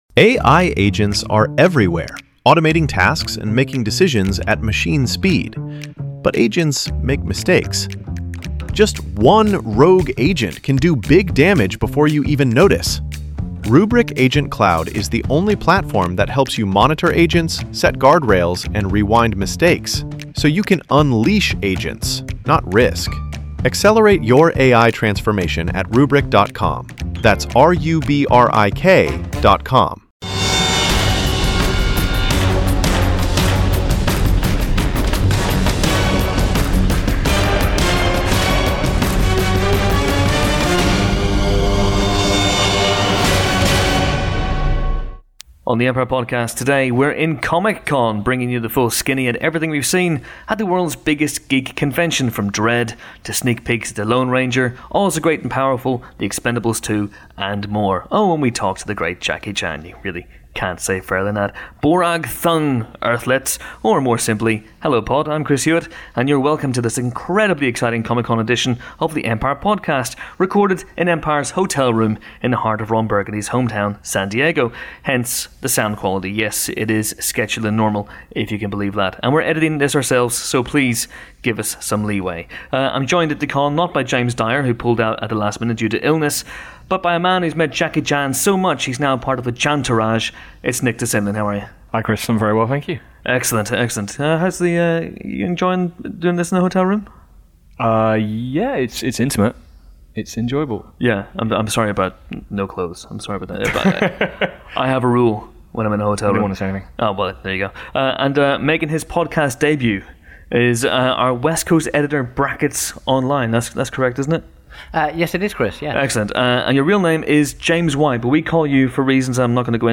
Empire heads to San Diego for Comic Con 2012, with our team recording the first of three special daily podcasts discussing and dissecting the best of what they've seen so far at the world's biggest geek gathering - and in this instance, interviewing the one and only Jackie Chan.